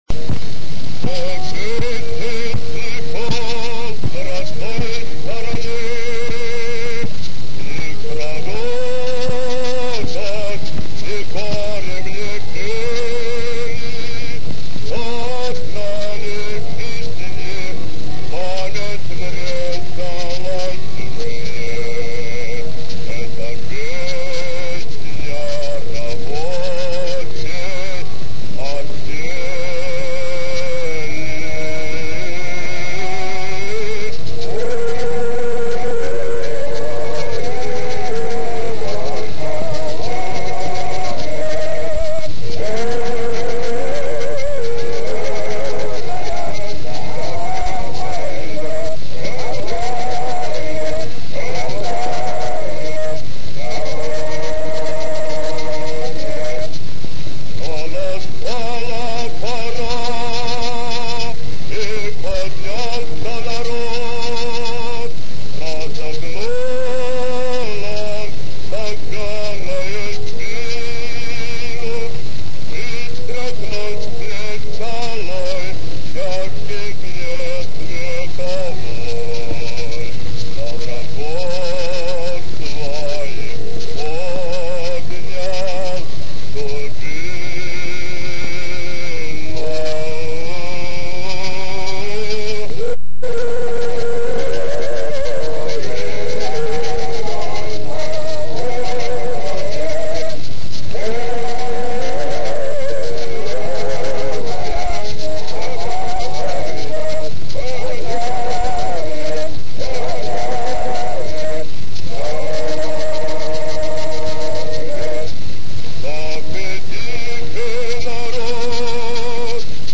Дореволюционная своеобразная запись.